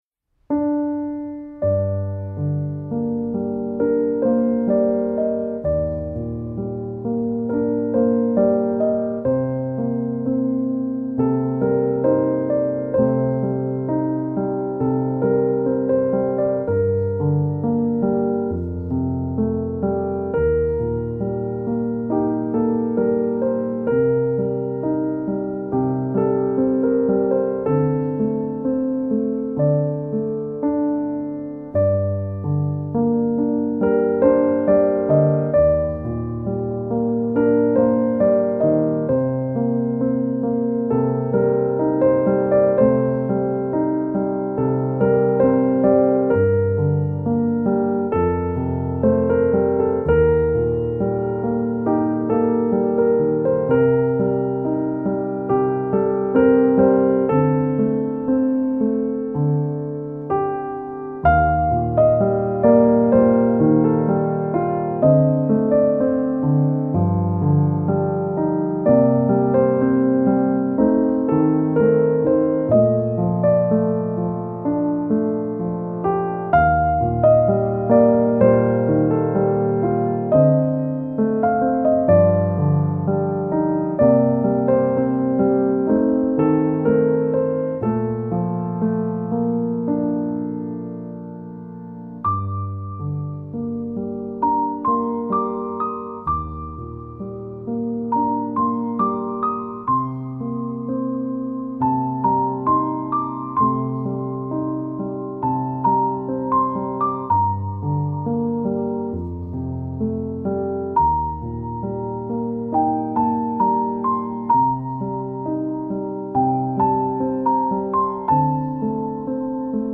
آهنگ بیکلام